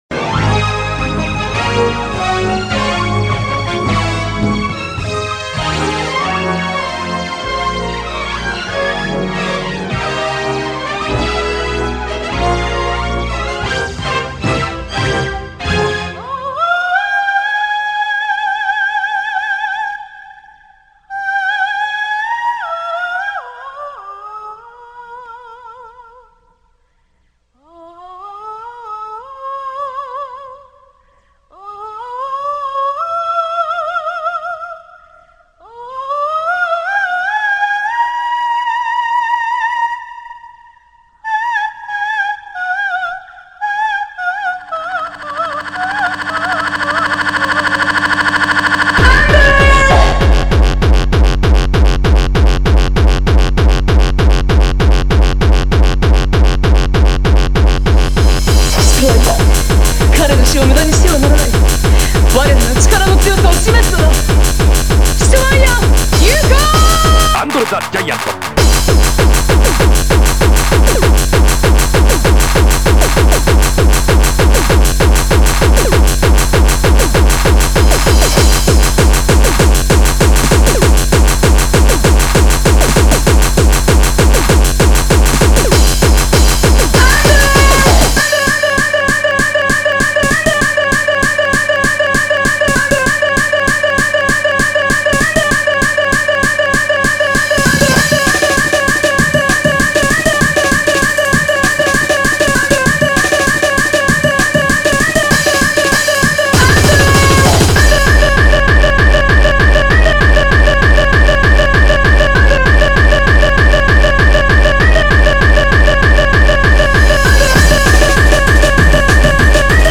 Style :GABBA